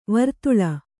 ♪ vartuḷa